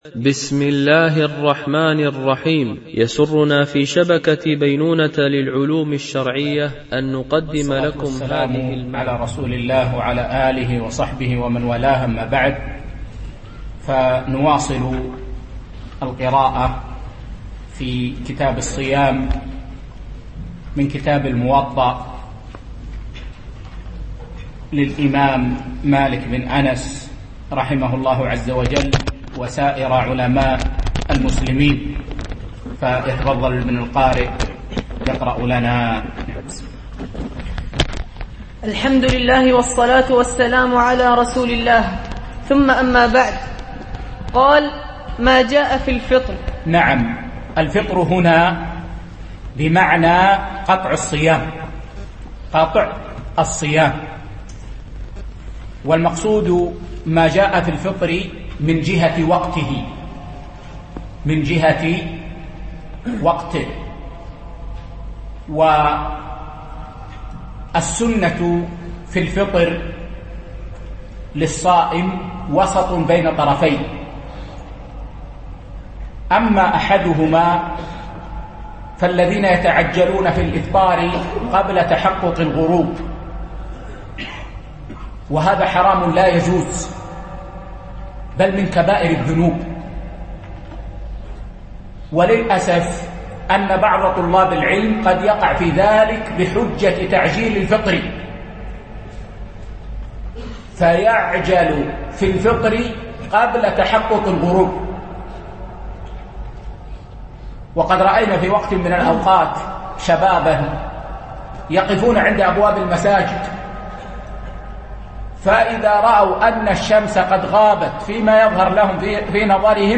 شرح كتاب الصيام من موطأ الإمام مالك ـ الدرس 2
دبي